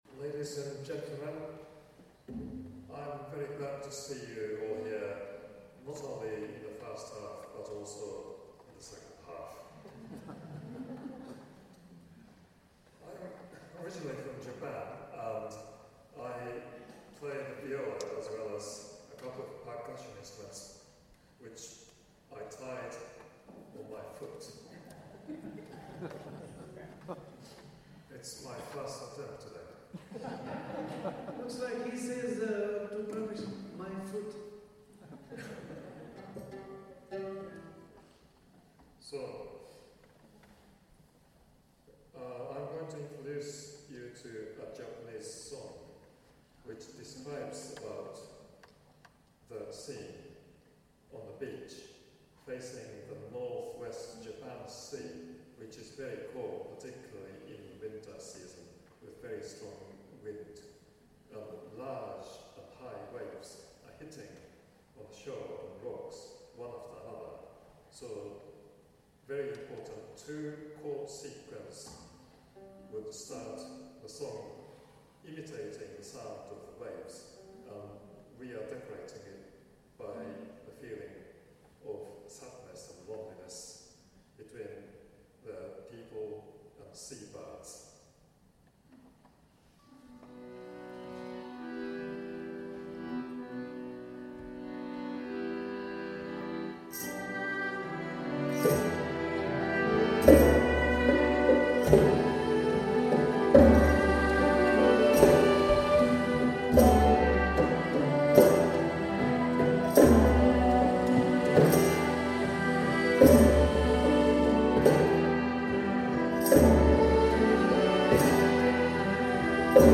at Keele University
accordion, which plays chords throughout with the piano as the other musicians play melodies and percussion.